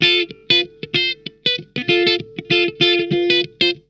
RARE HI LP.wav